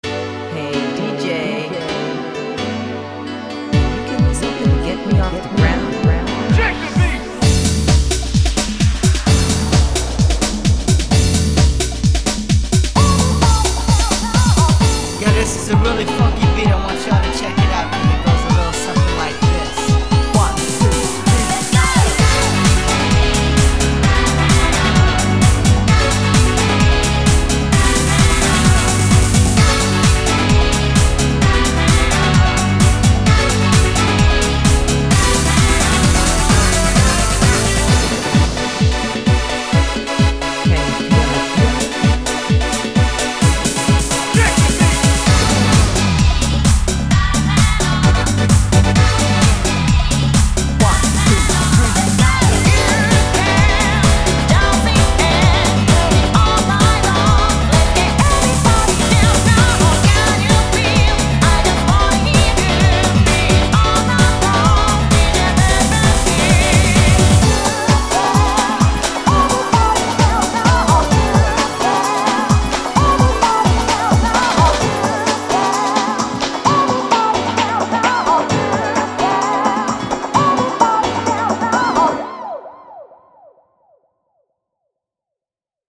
BPM130
Audio QualityMusic Cut
HOUSE REVIVAL